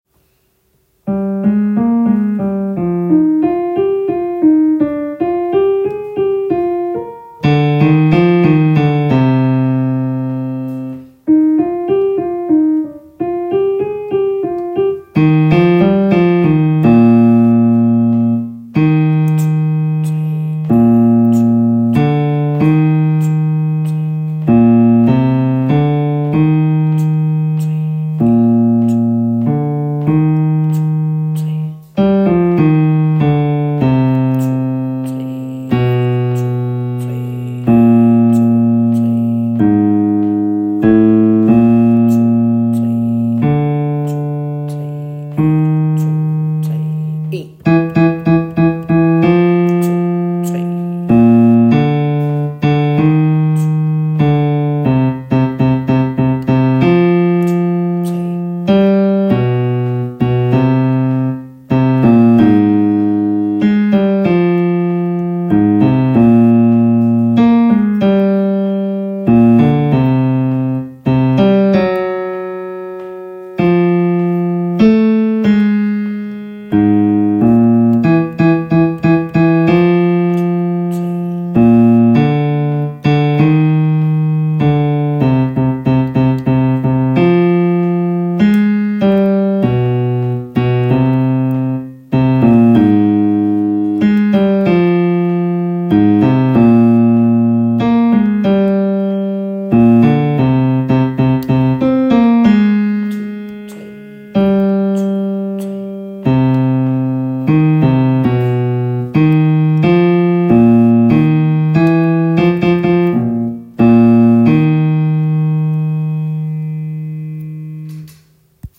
Kun koret